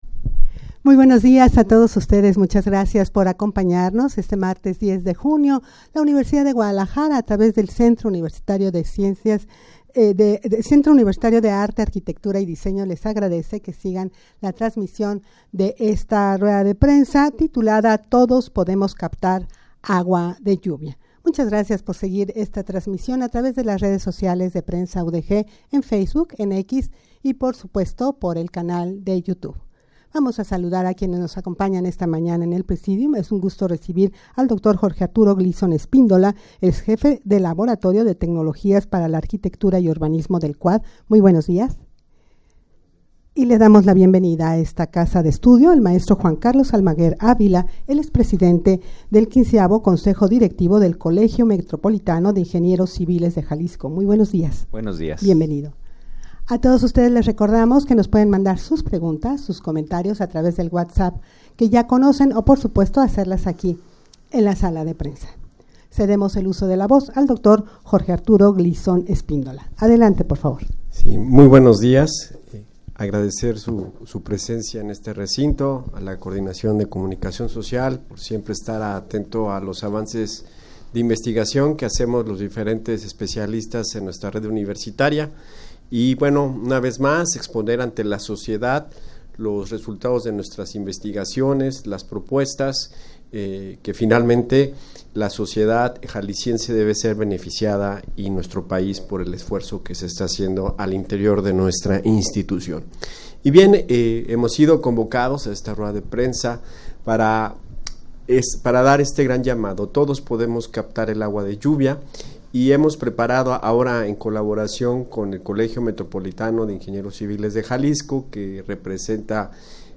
Audio de la Rueda de Prensa
rueda-de-prensa-todos-podemos-captar-agua-de-lluvia.mp3